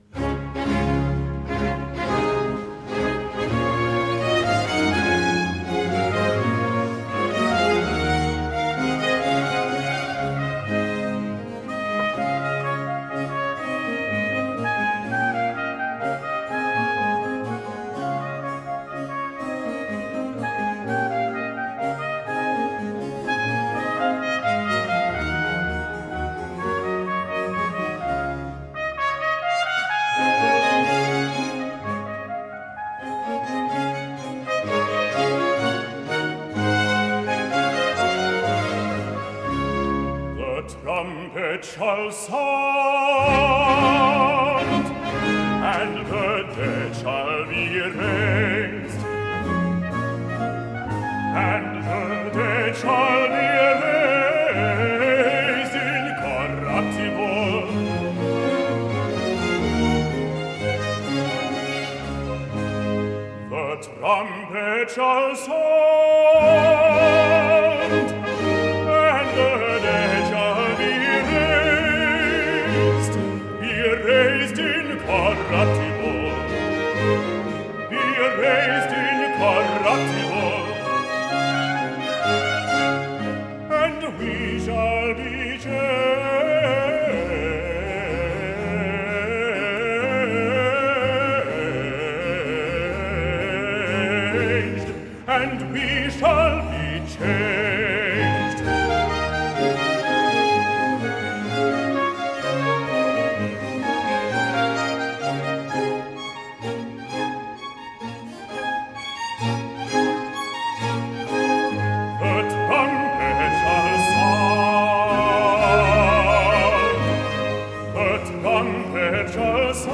De la tercera part de l’oratori escoltem la magnífica ària “The Trumpet shall sound” a càrrec del baix Roderick Williams.
MUSIKFEST ERZGEBIRGE – Eröffnungskonzert
Roderick Williams, Baix
Sankt Marienkirche Marienberg